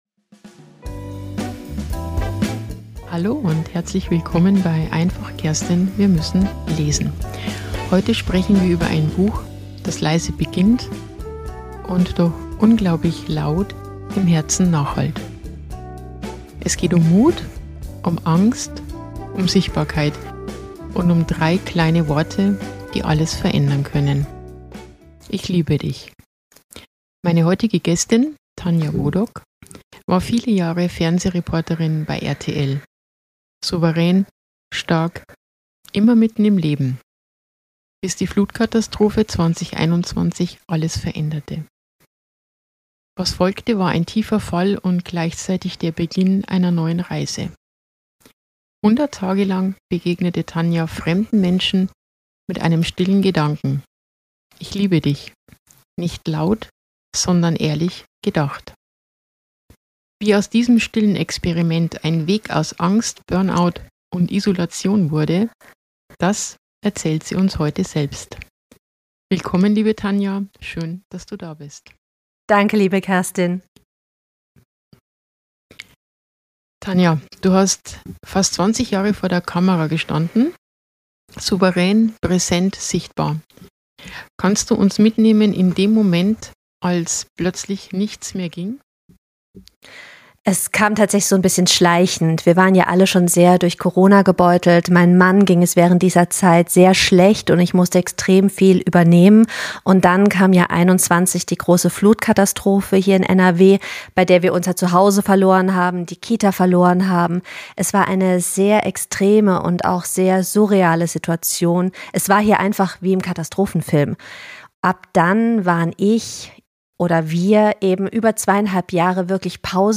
Ein Gespräch über Heilung, Selbstmitgefühl und den Mut, sich wieder zu zeigen.